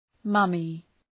Shkrimi fonetik {‘mʌmı}